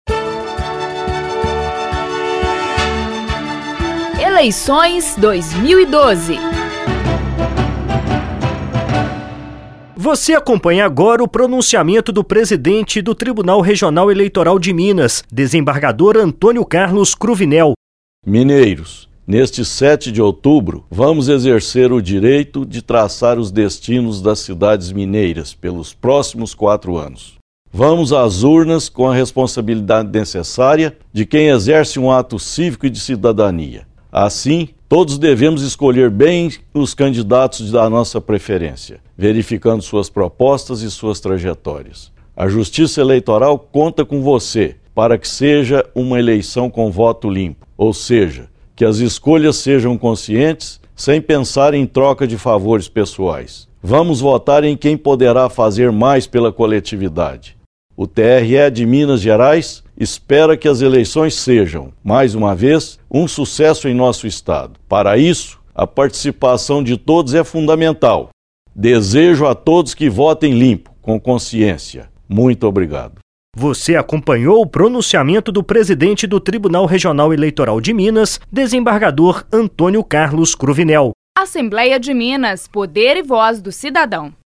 Eleições 2012 - Pronunciamento do Presidente do Tribunal Regional Eleitoral - Minas Gerais